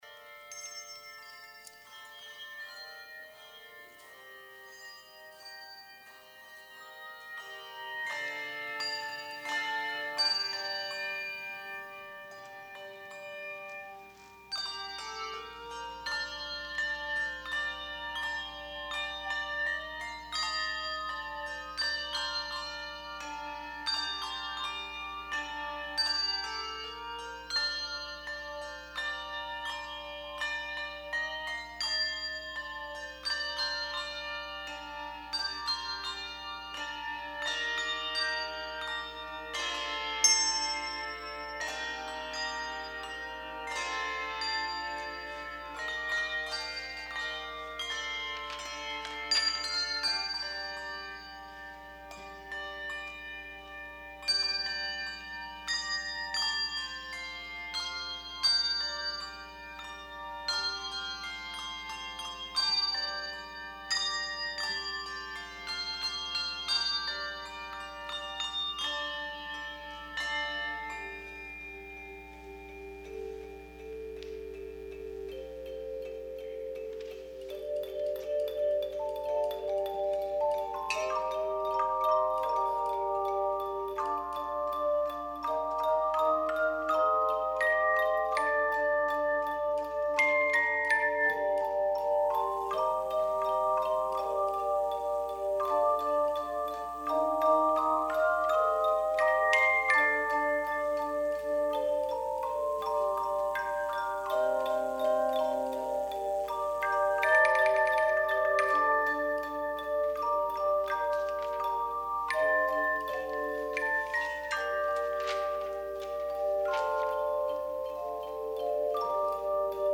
Performer:  Handbell Choir